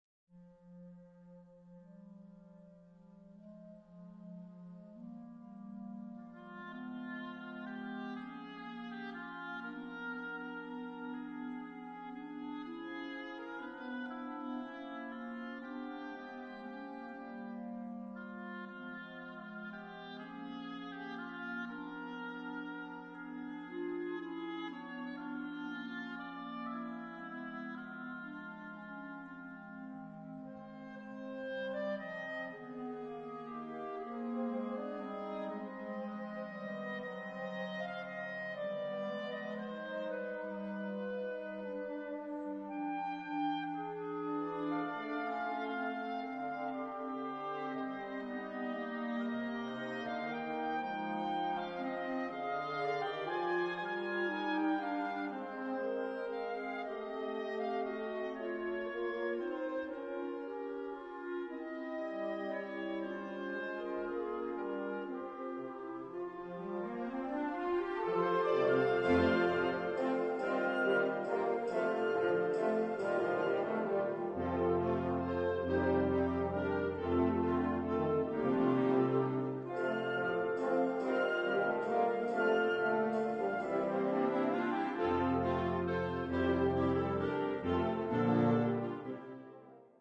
Kategorie Blasorchester/HaFaBra